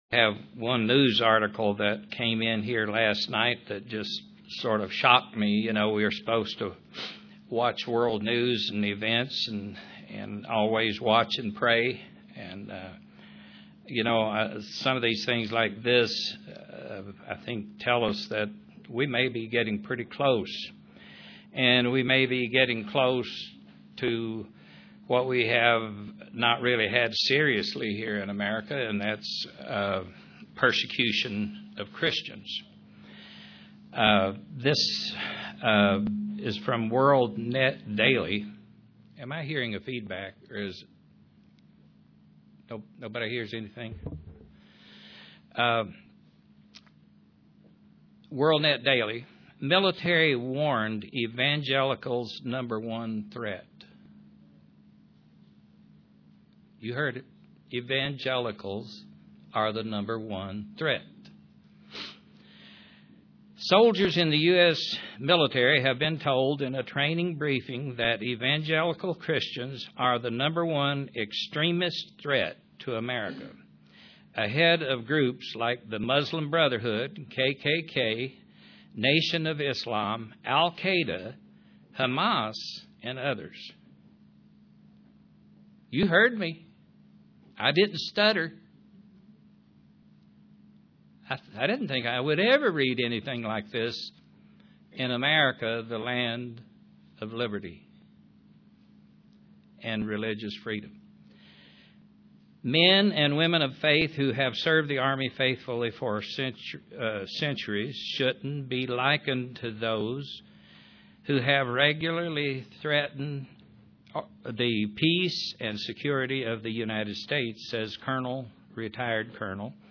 Print A Christian needs to grow in maturity UCG Sermon Studying the bible?
Given in Kingsport, TN